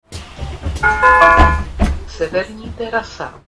Trolejbusy totiž disponují přehlášenými zastávkami a dalšími zvukovými sekvencemi.
- Ukázku hlášení zastávky po příjezdu si